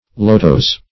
Lotos \Lo"tos\, n. [NL.] (Bot.)